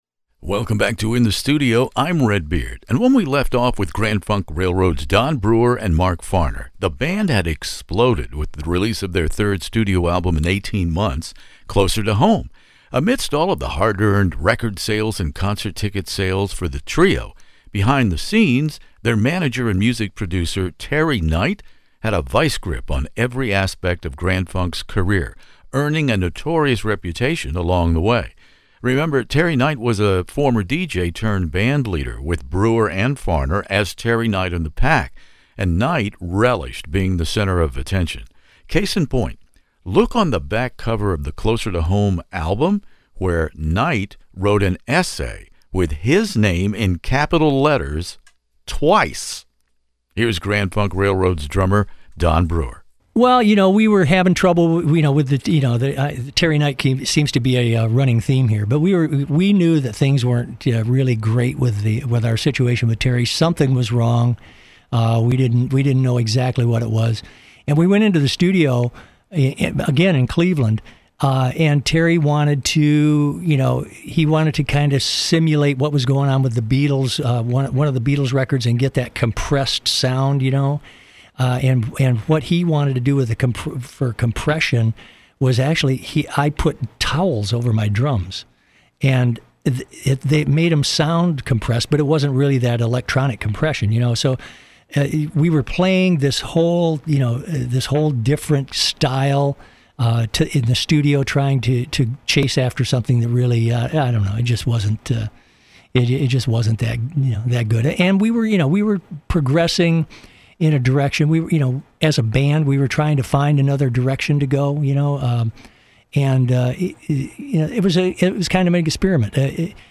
One of the world's largest classic rock interview archives, from ACDC to ZZ Top, by award-winning radio personality Redbeard.
When we left off in part one with my guests Grand Funk Railroad’s drummer/vocalist/songwriter Don Brewer and former singer/ guitarist/ songwriter Mark Farner, the band had exploded with the release of their third studio album in eighteen months, Closer to Home .